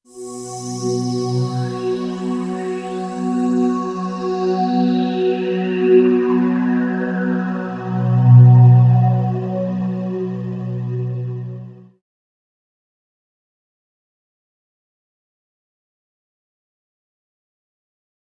WAV · 785 KB · 單聲道 (1ch)